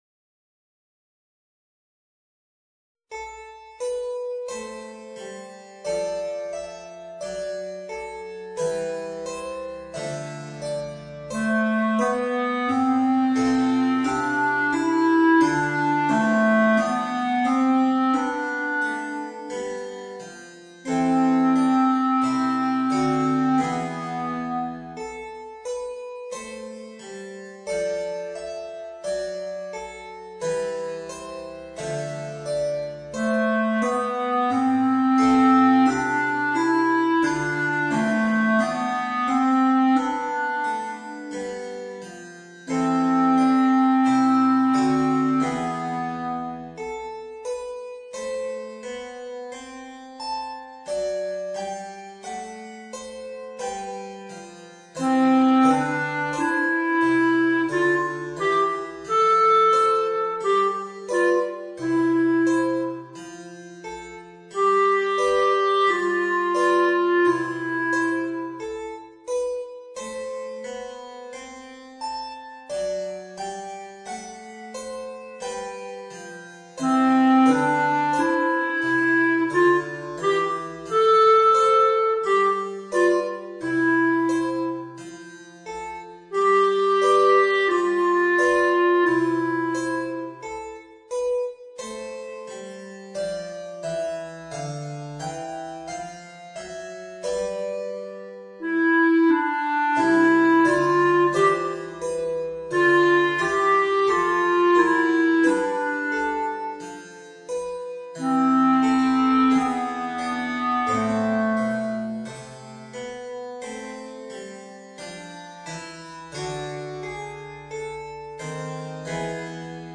Clarinet and Harpsichord